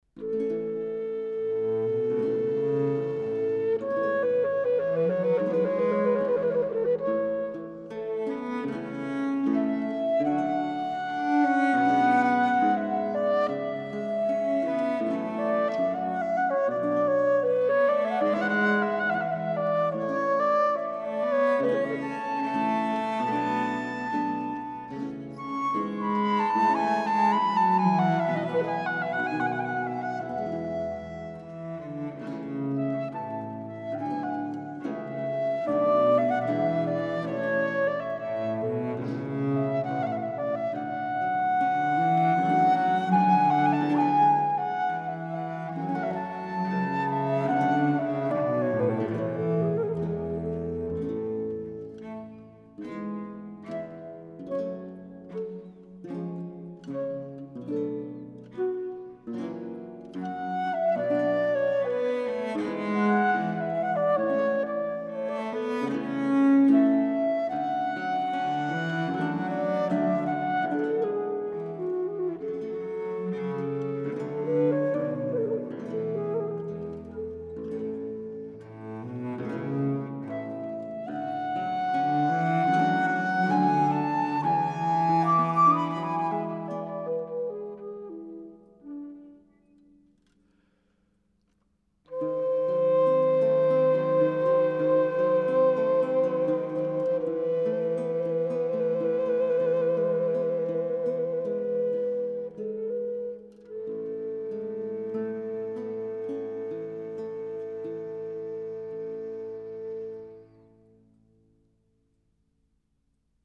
La flûte et le clavecin nous prennent alors par la main pour un moment d'extrême délicatesse à la fois de danses et de rêverie.
Sonate VII en Si bémol majeur (Flûte et basse continue)